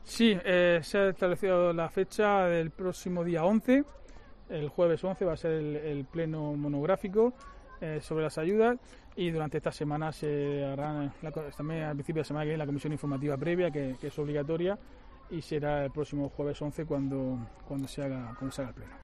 Diego José Mateos, alcalde de Lorca sobre Pleno extraordinario ayudas